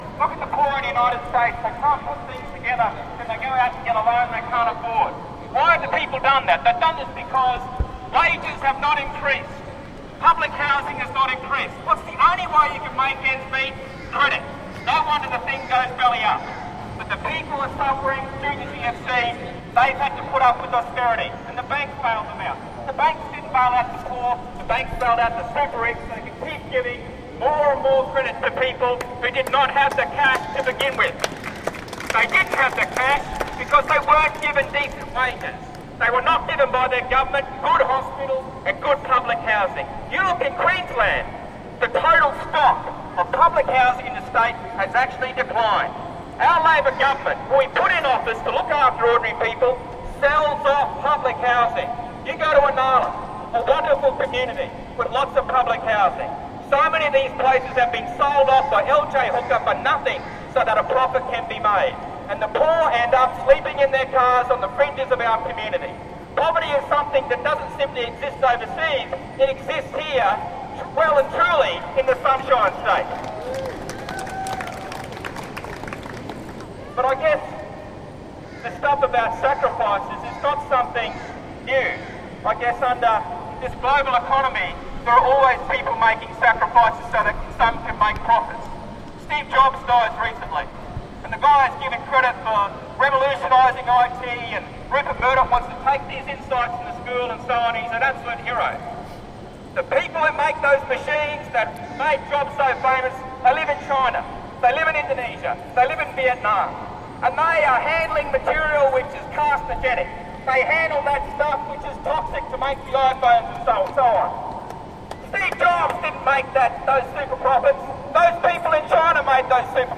Here are two speeches from Day One of Occupy Brisbane—